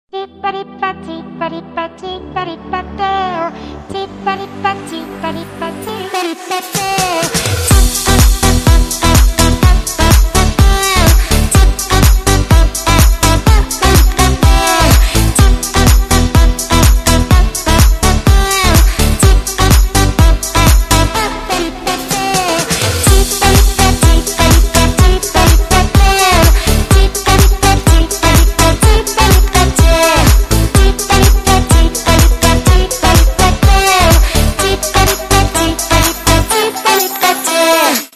веселые
dance
Electronic
EDM
house
смешной голос
Забавный голос)